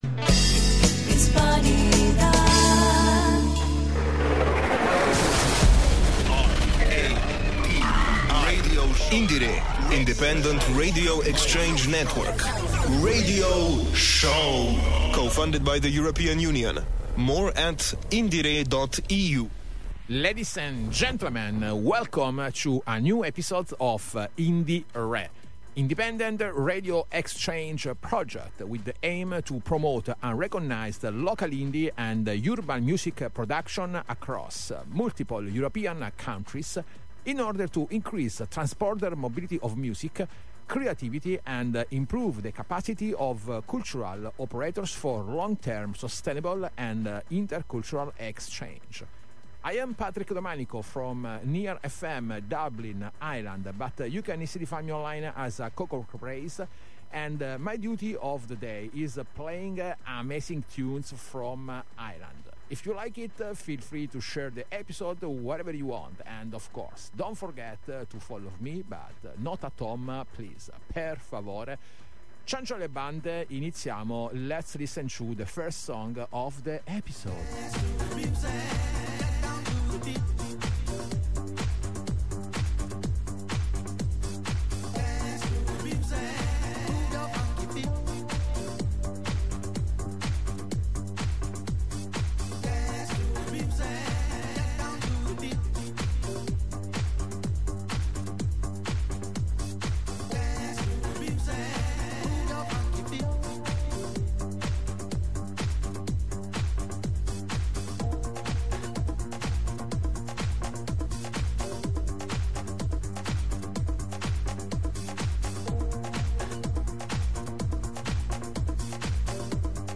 Featuring a rich variety of sounds from indie-pop to post-punk and experimental music, this edition promises a deep dive into the creativity that is flourishing in the Irish music scene.